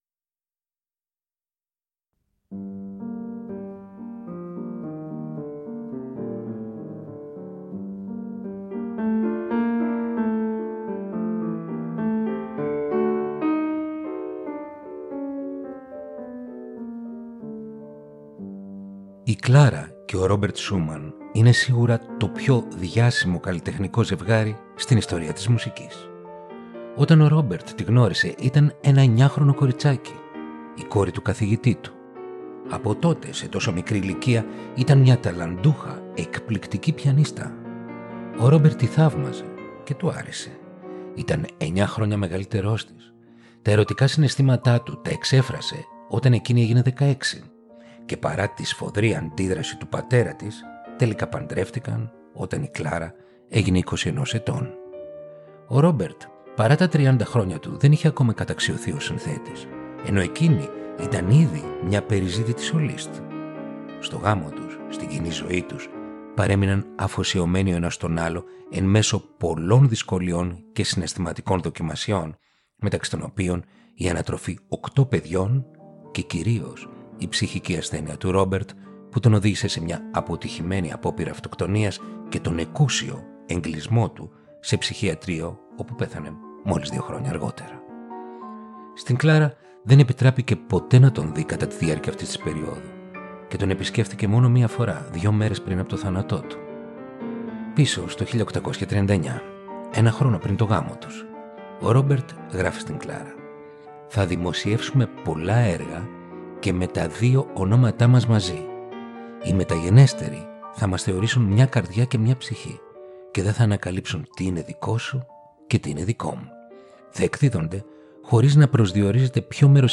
Ρομαντικά κοντσέρτα για πιάνο – Επεισόδιο 9ο